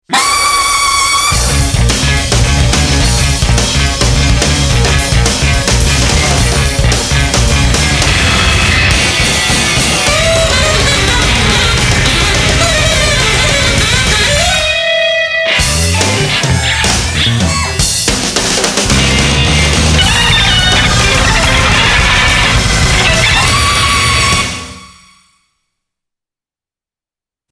Don’t have your speakers on too loud… here’s
28 seconds of delish jazz-core
The bleeding edge of art jazz…